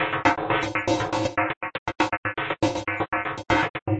描述：一个循环的120bpm循环电子节拍与一些合成器。使用FL Studio创建。
Tag: FL-工作室 合成器 120-BPM 风铃 节拍 贝司